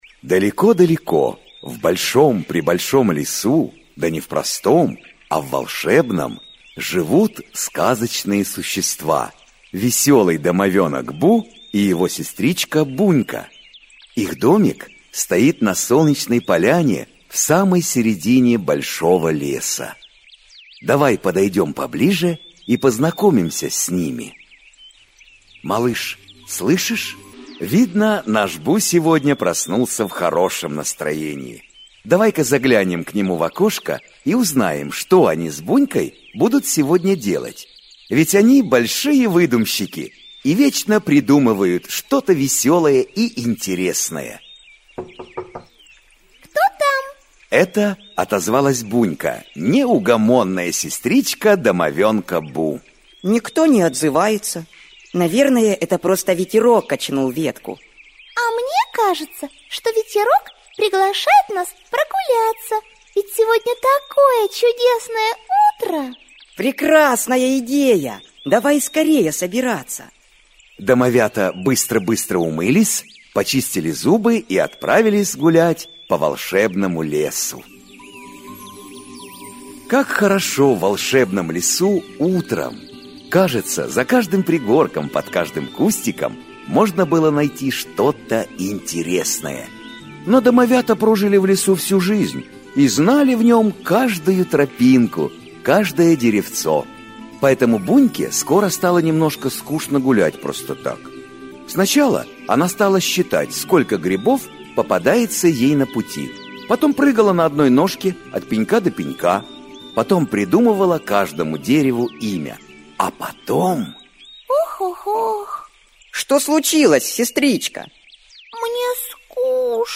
Аудиокнига Хочу все знать. Как вести себя на улице | Библиотека аудиокниг